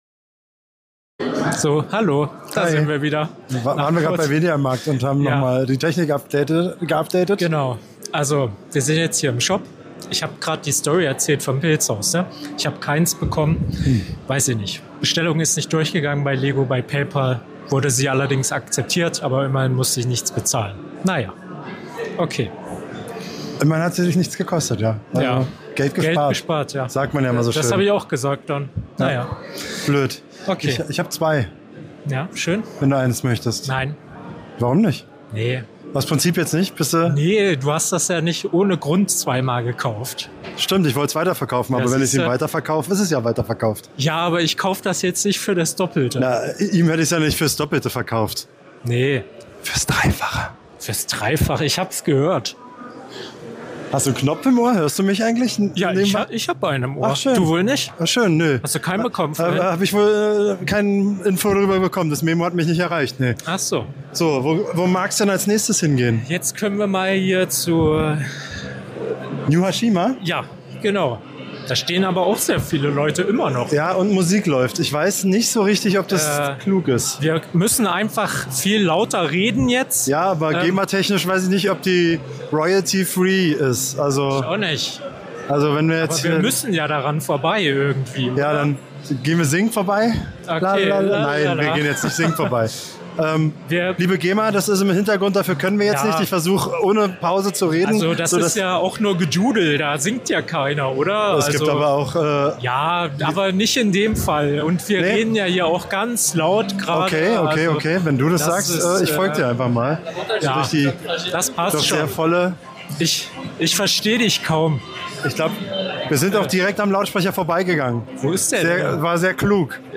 #32 - Live von der Bricking Bavaria 2024 in Fürth | Teil 2